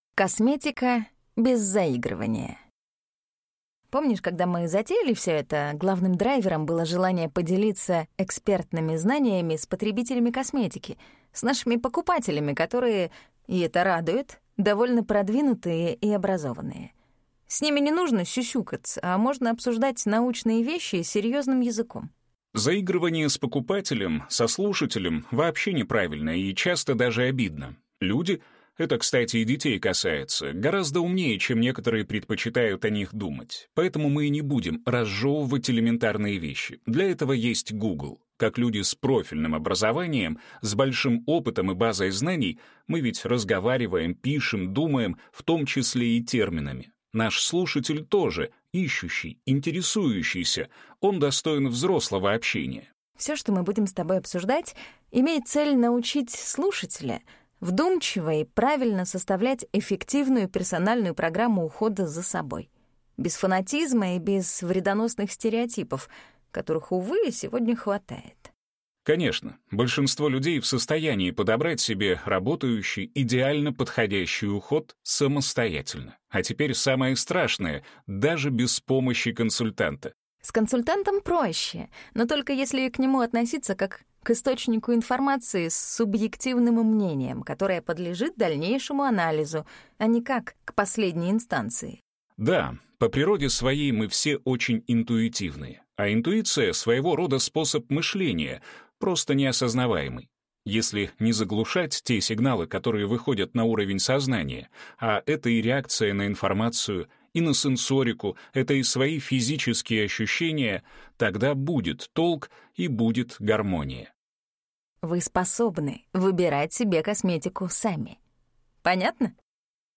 Аудиокнига Косметика без заигрывания | Библиотека аудиокниг